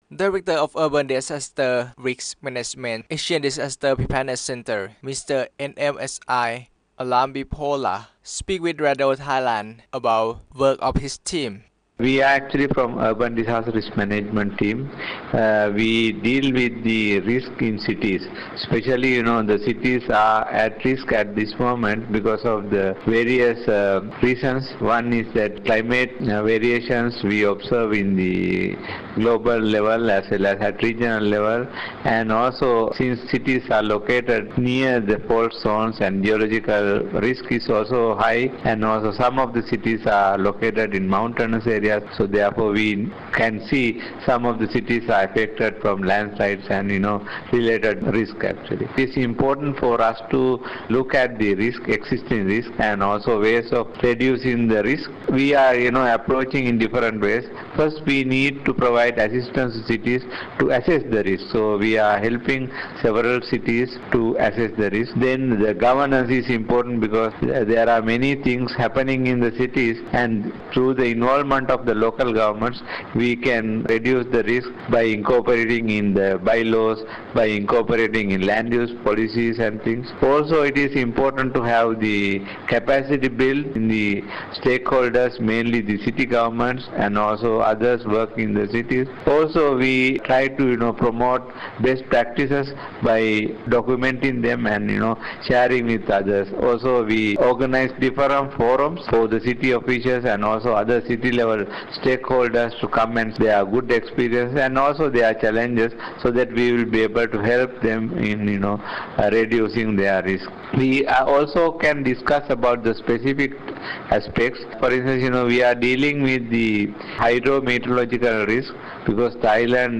This recorded interview has been aired through FM. 88
Interview_Radio Thailand_6June.mp3